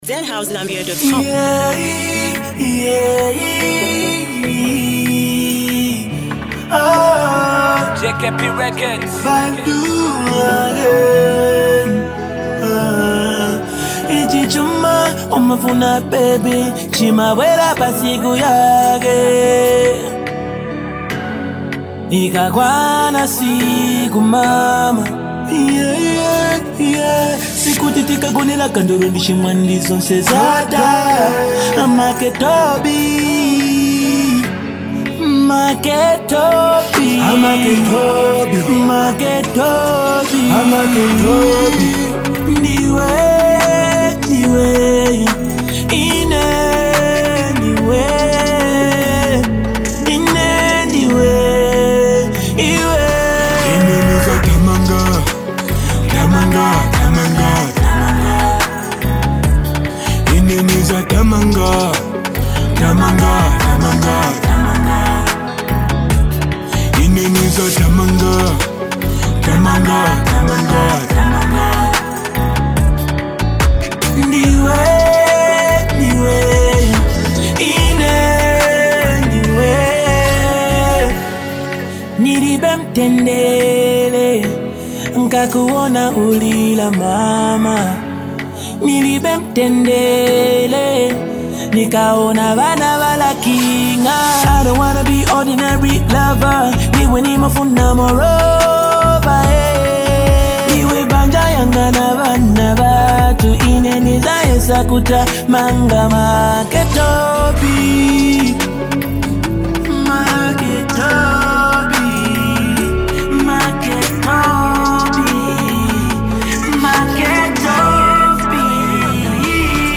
heartfelt love song